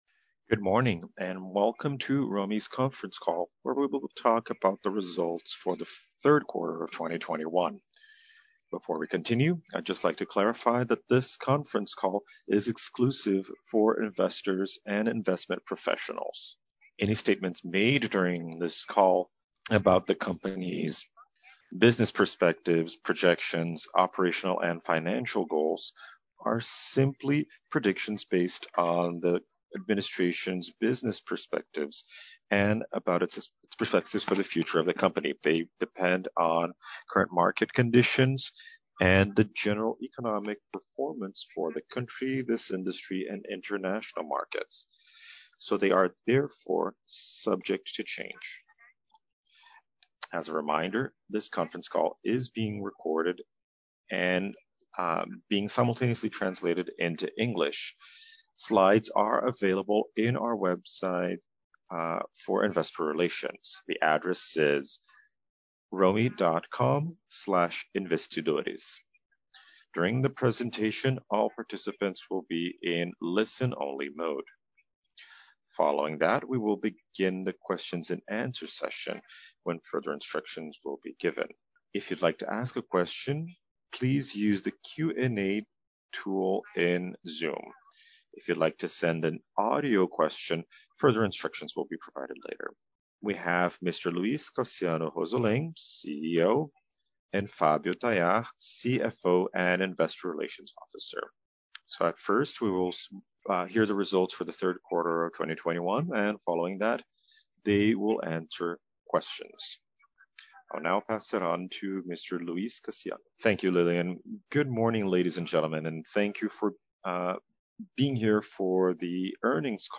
Audio from Quarter Teleconference